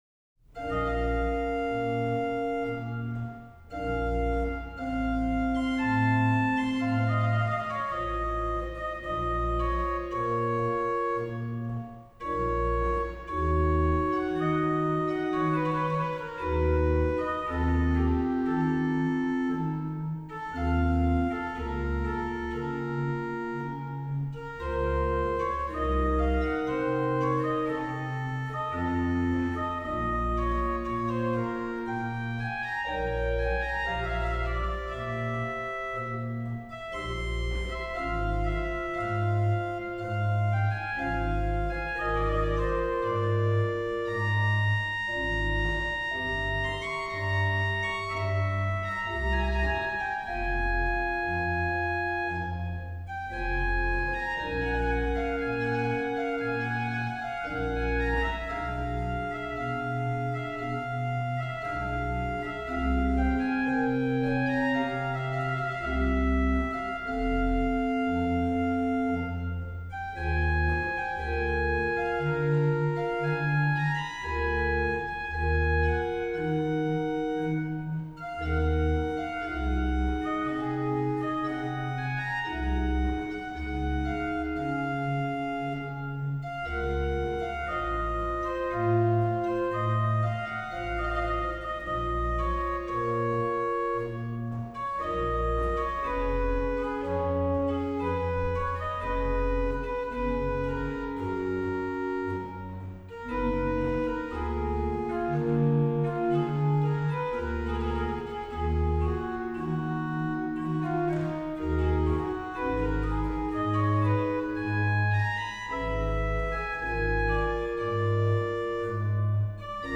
RH: OW: Lpr4 (8ve lower)
Ped: Sub16, BW/Ped; BW: Ged8
HW: Pr8, Unda8